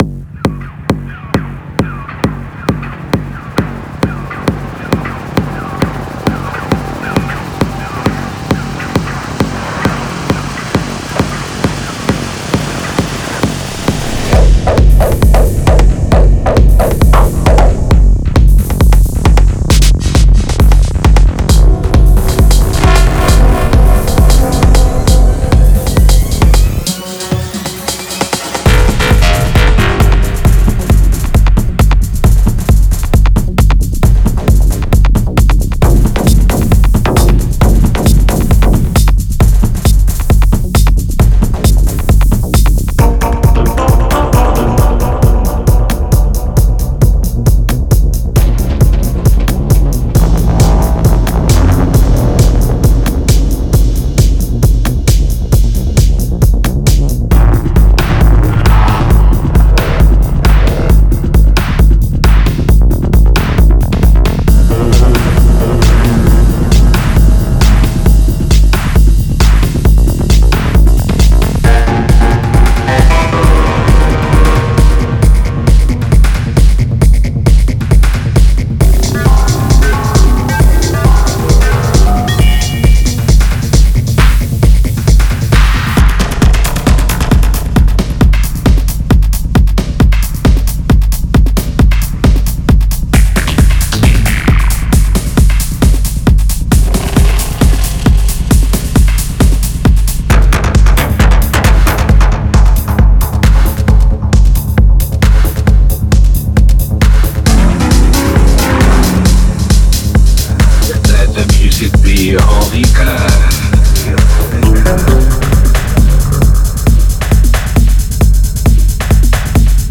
テクノ系では特に使い勝手も良く、 重宝するサンプルパックです。